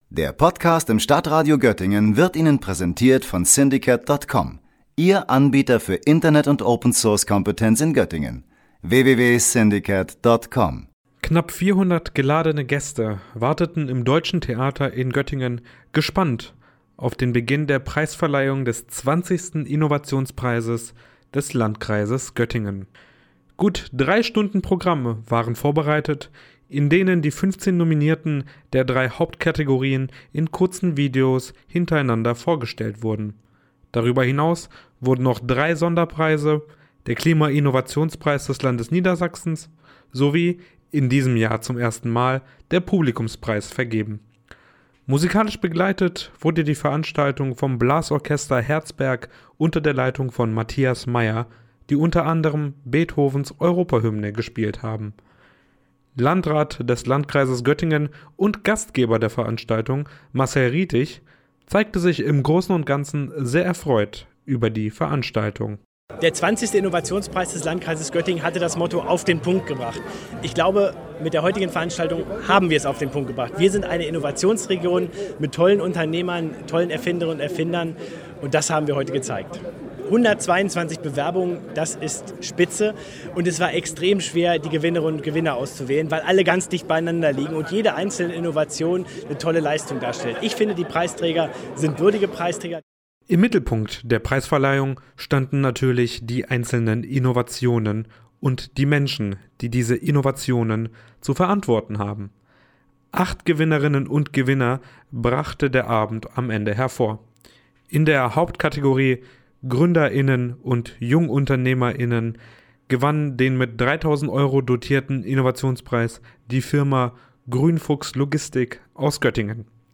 Nein, an diesem Abend fand die Preisverleihung des 20. Innovationspreises des Landkreises Göttingen statt. Über 200 innovative Projekte hatten sich beworben, um einen der begehrten Preise gewinnen zu können.